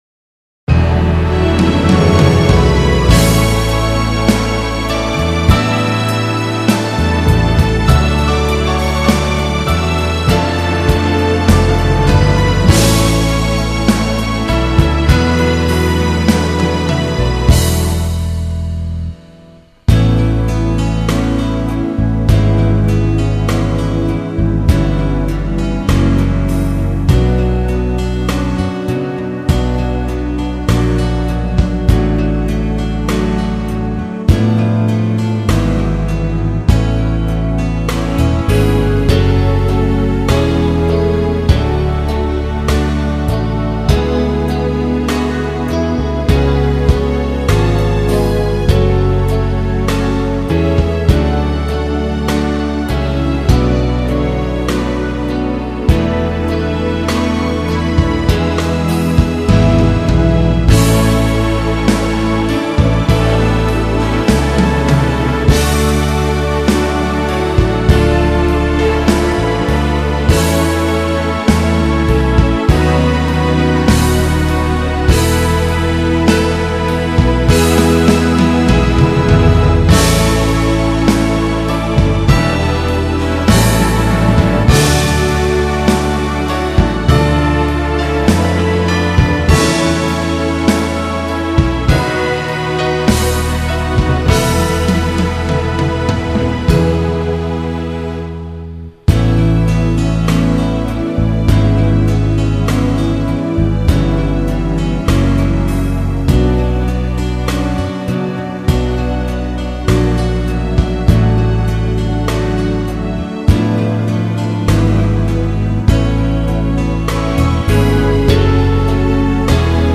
Genere: Lento
Scarica la Base Mp3 (3,43 MB)